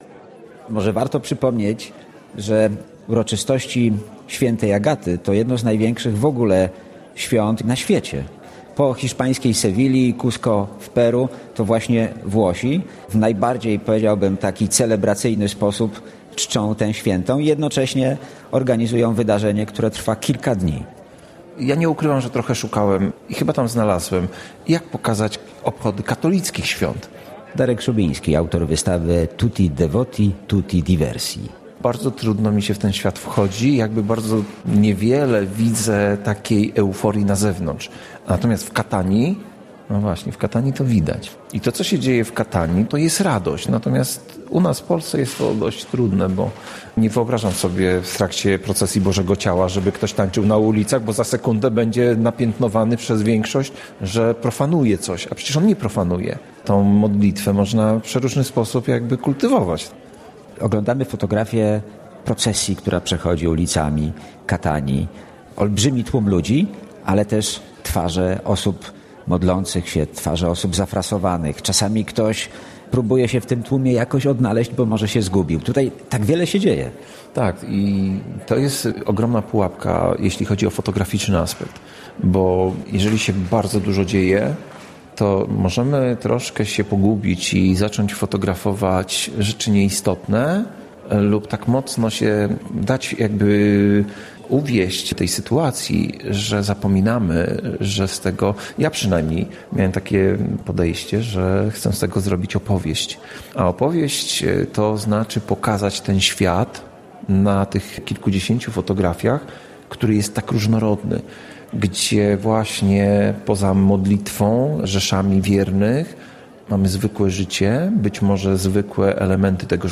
Z autorem wystawy